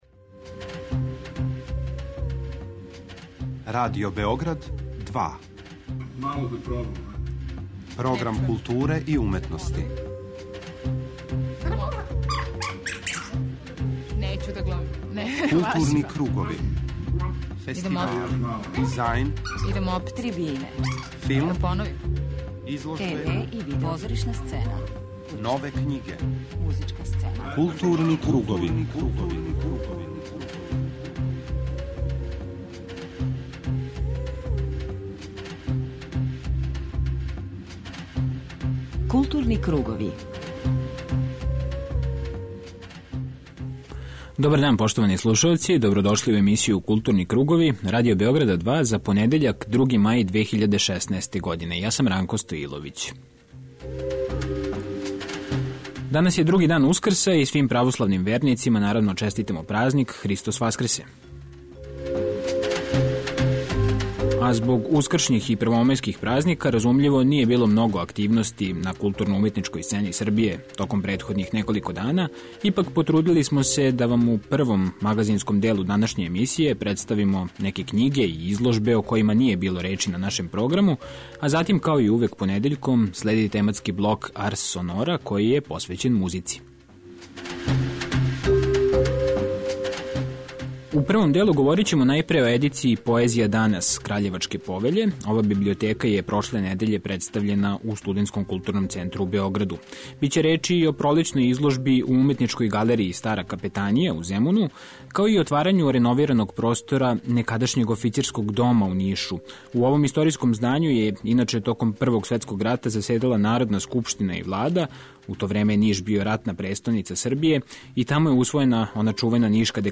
преузми : 41.14 MB Културни кругови Autor: Група аутора Централна културно-уметничка емисија Радио Београда 2.